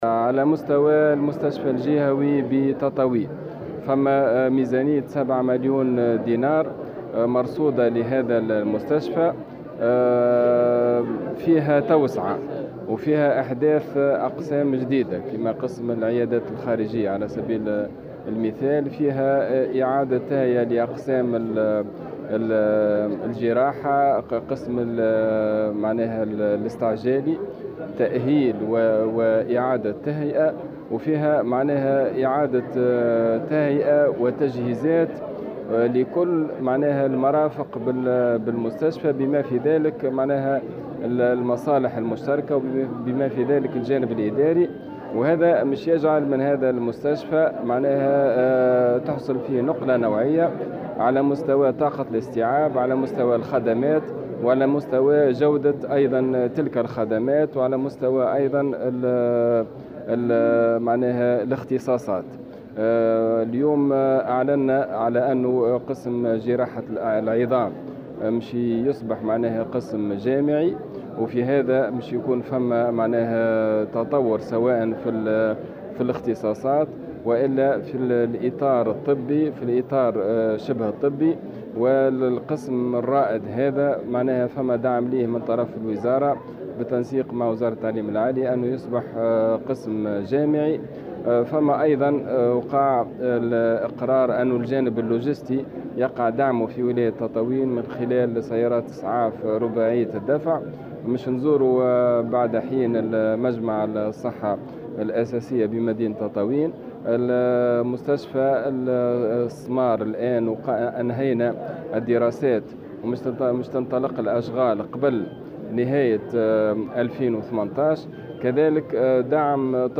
وأضاف الحمامي في تصريح لمراسل "الجوهرة أف أم" بالجهة أنه تم أيضا تحويل قسم جراحة العظام إلى قسم جامعي، إضافة إلى توفير سيارات إسعاف رباعية الدفع ودعم طب الاختصاص بالجهة.